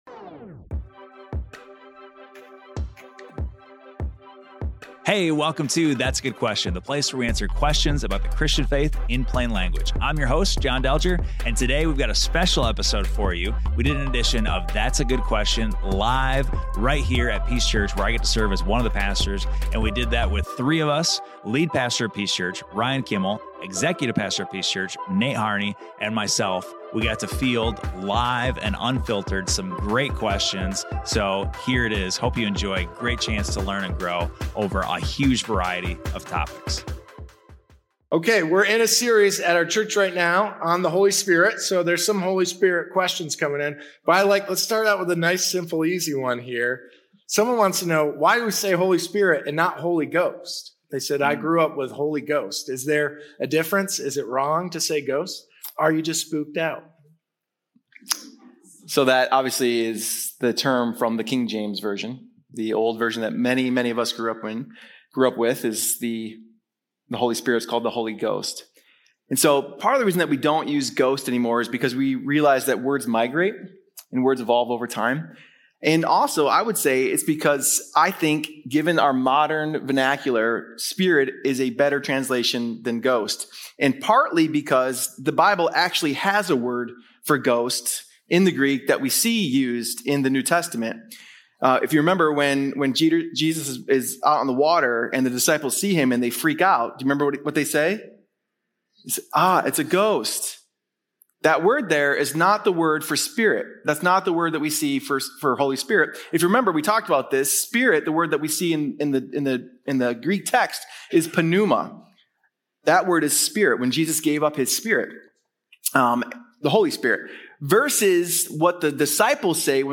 Join us for an honest, grace-filled conversation rooted in Scripture and shaped by real-life tensions.